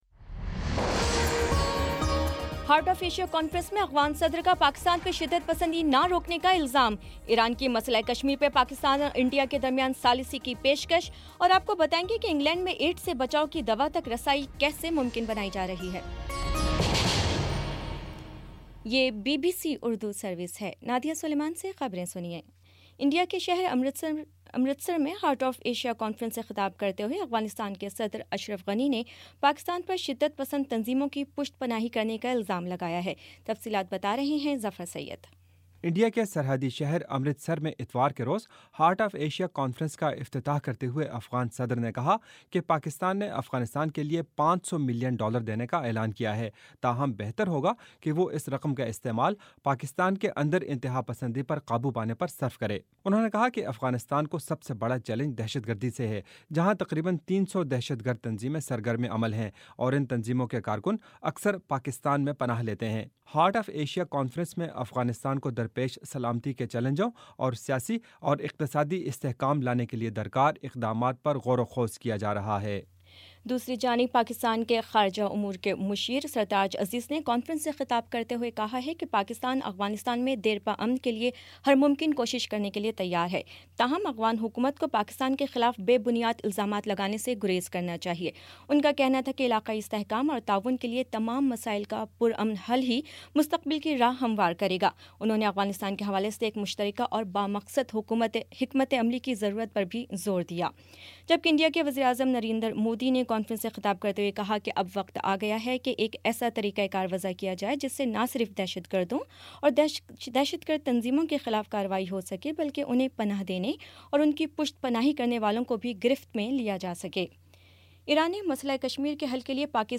دسمبر 04 : شام پانچ بجے کا نیوز بُلیٹن